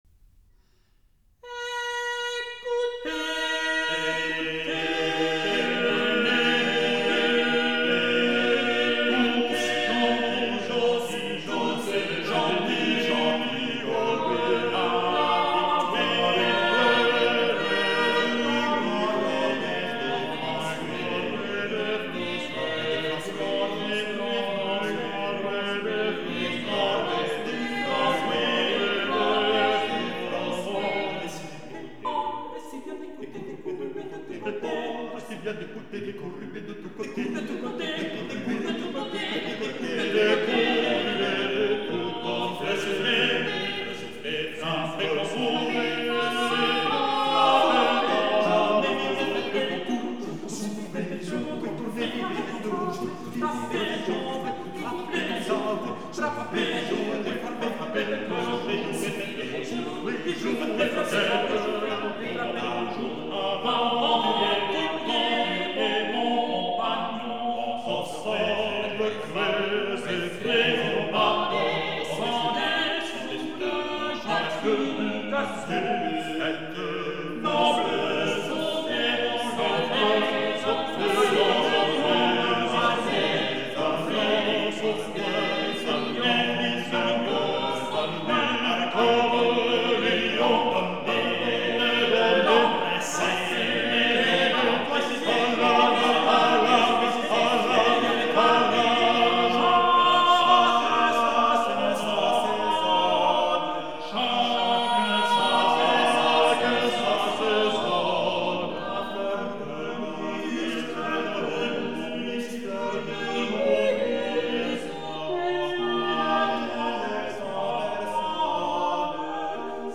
La guerre. Notare l'imitazione dei suoni della guerra e del ritmo del combattimento.